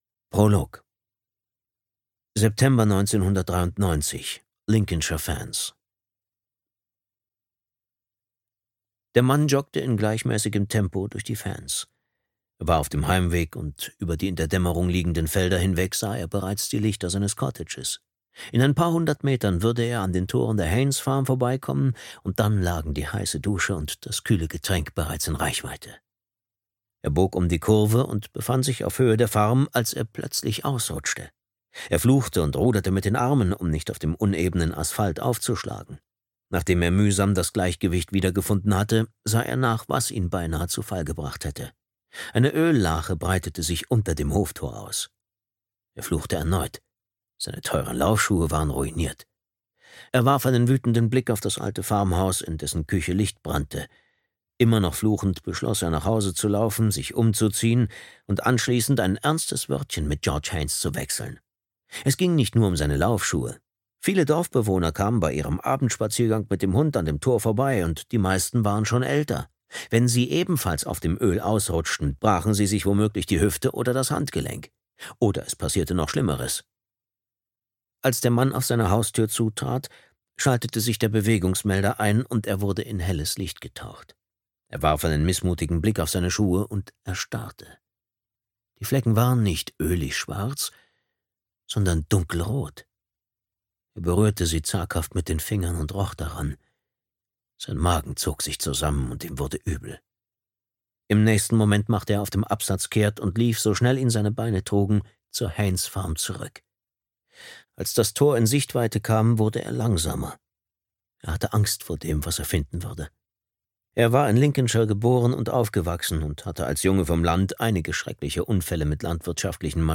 Der Sohn der Mörderin (DE) audiokniha
Ukázka z knihy
der-sohn-der-morderin-de-audiokniha